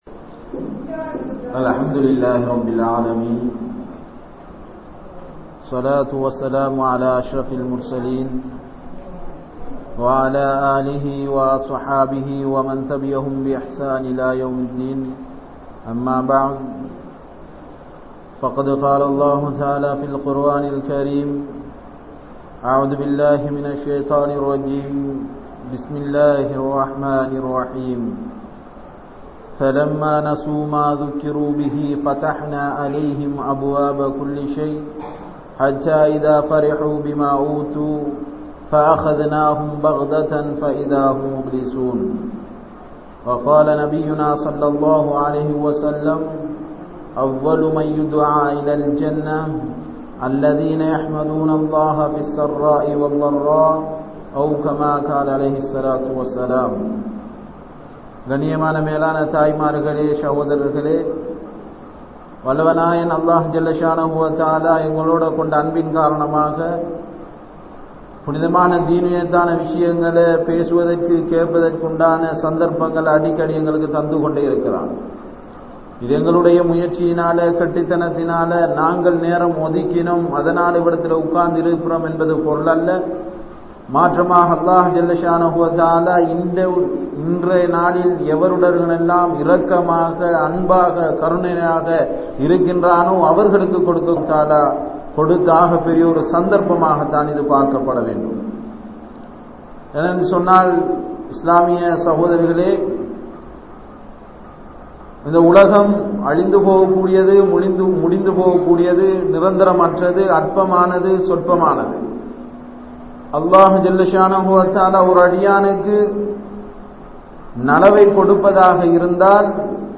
Pillaihalai Kurai Sollum Indraya Petroarhal (பிள்ளைகளை குறை சொல்லும் இன்றைய பெற்றோர்கள்) | Audio Bayans | All Ceylon Muslim Youth Community | Addalaichenai